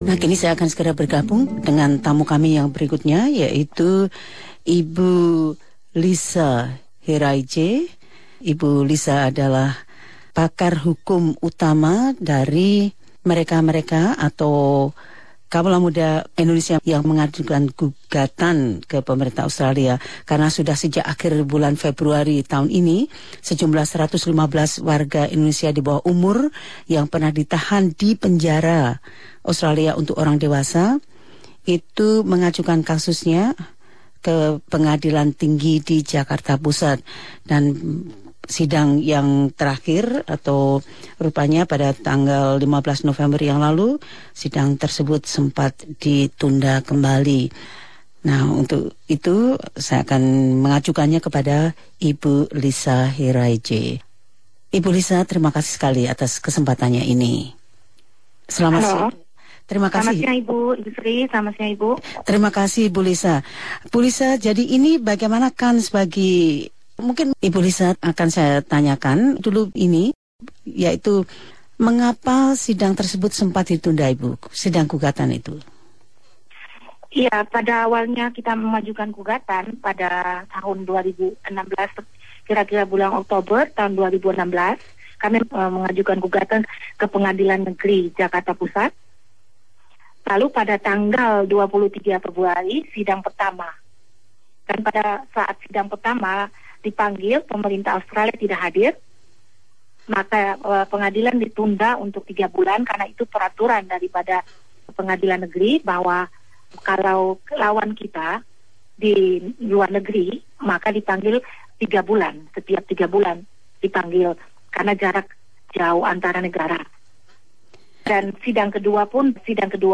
Pengacara Indonesia